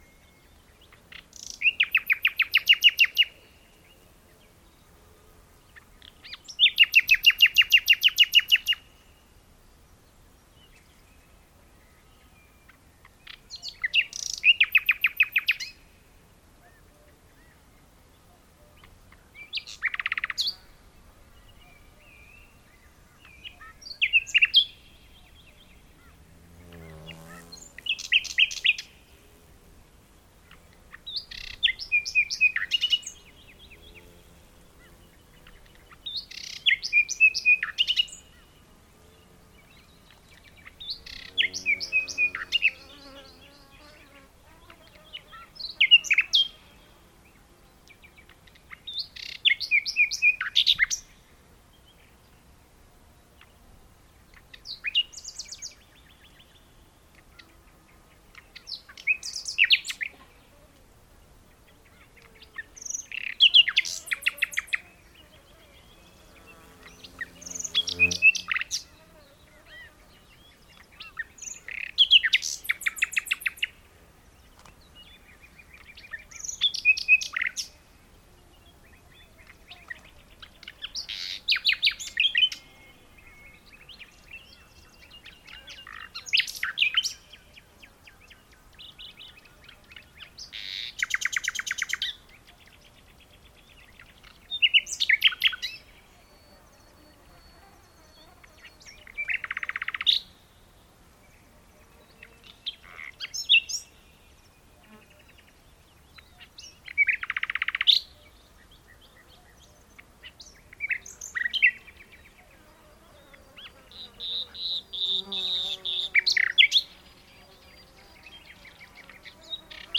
qu'est ce que le chant du coucou ?